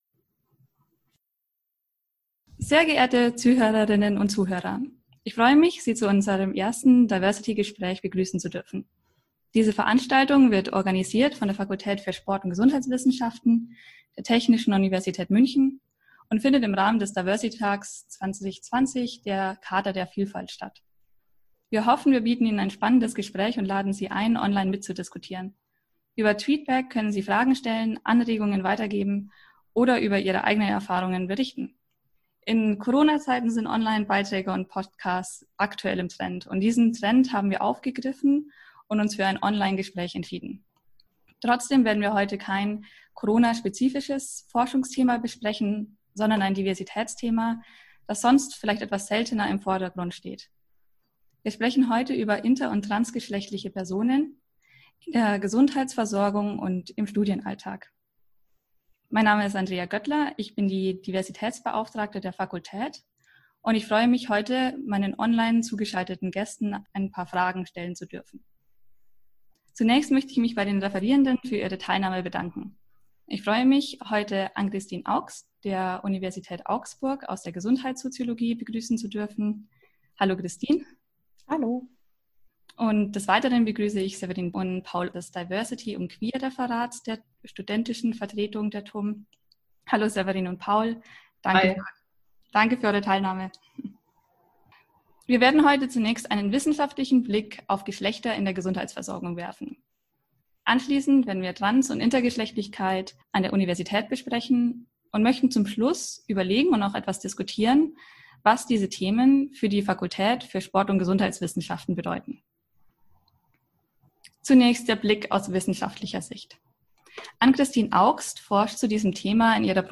The Department of Sports and Health Sciences participated this year with a diversity podcast on the topic of Trans* and Inter*. In response to the corona restrictions, the event was organized as an online podcast.